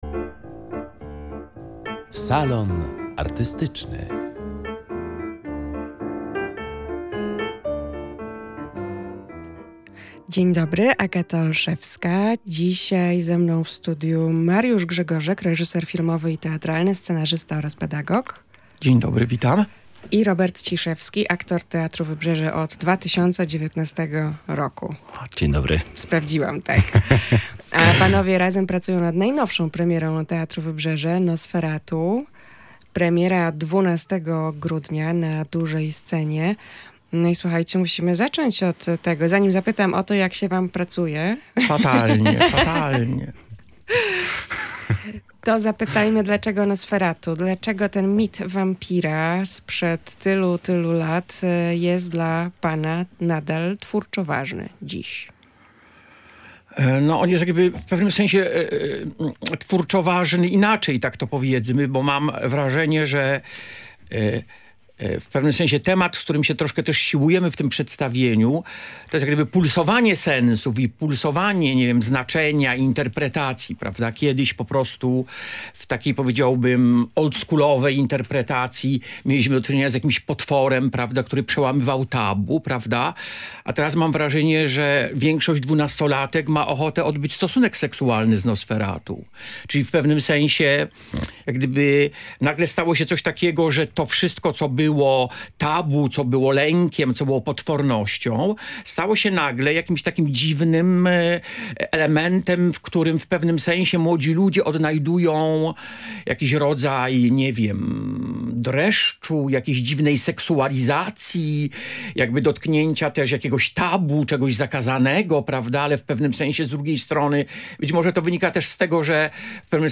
„Nosferatu” w Teatrze Wybrzeże. Rozmowa